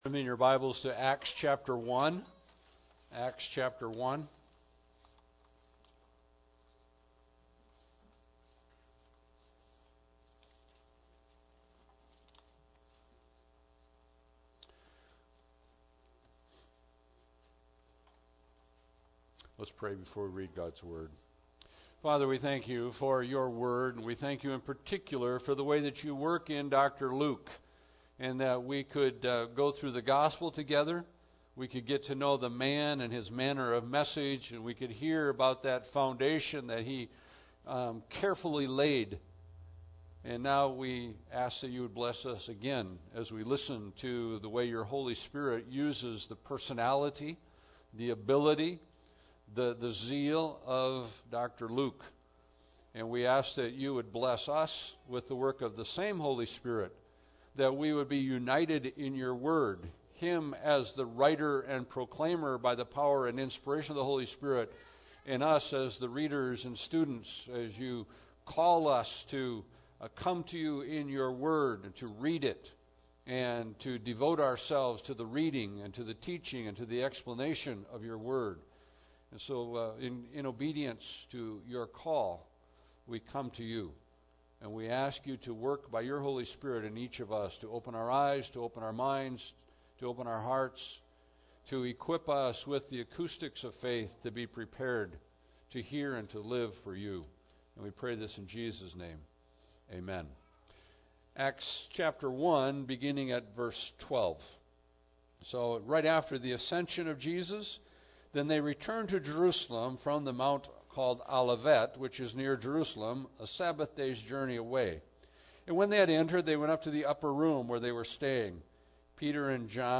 Passage: Acts 1:12-26 Service Type: Sunday Service